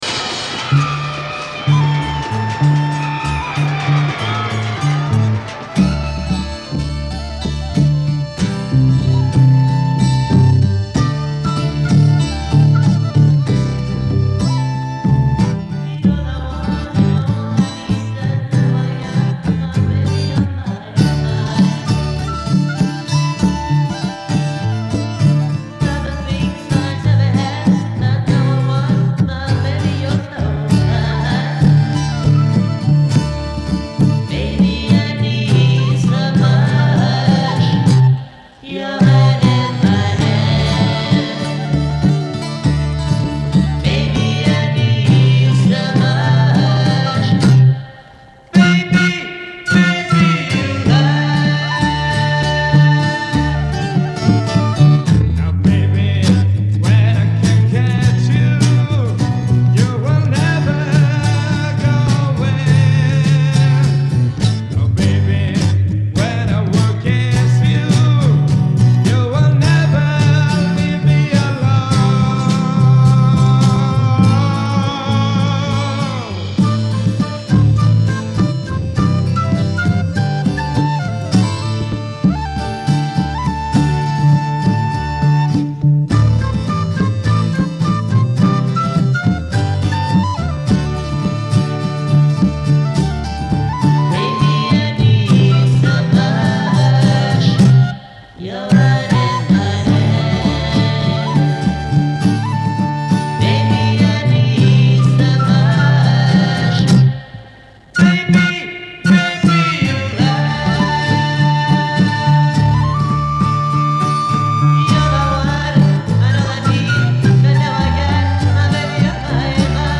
Жанр: Beat Music, Garage Rock, Psychedelic Rock
бит-ритмы того времени с психоделическими мотивами